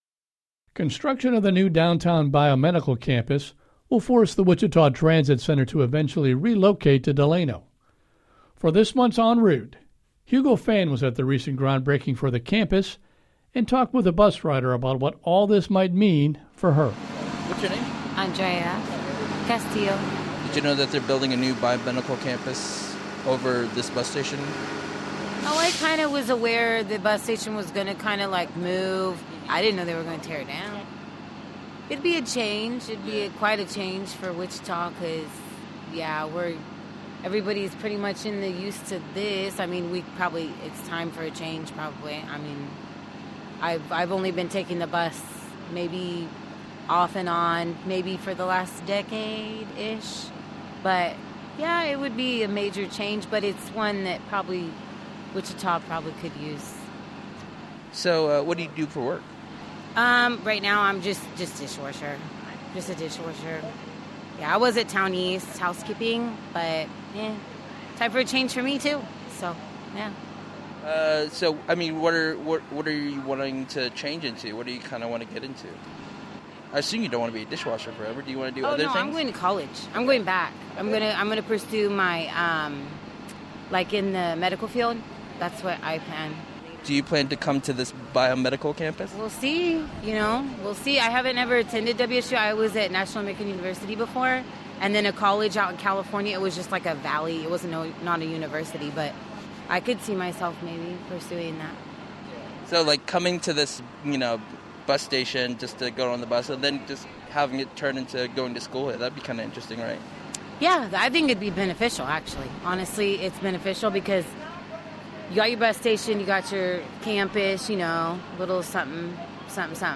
En Route - Talking with a bus rider about the upcoming changes to the Wichita Transit Center